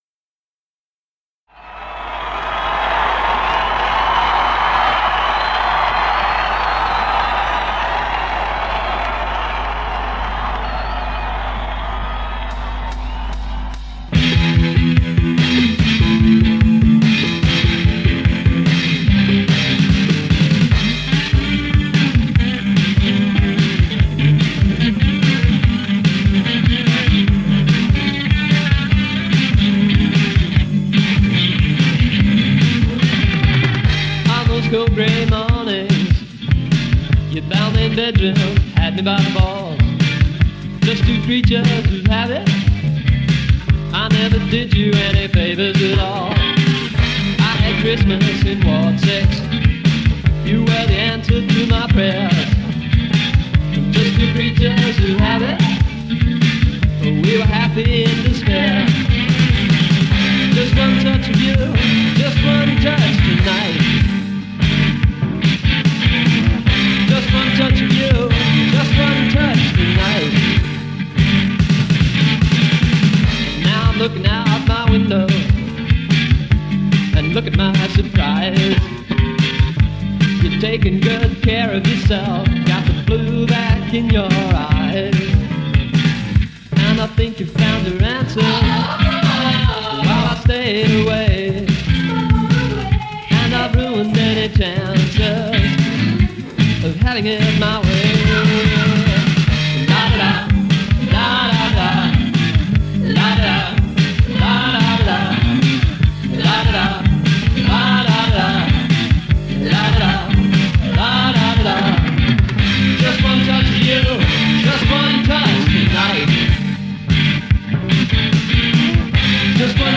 guitars/vocals/harmonica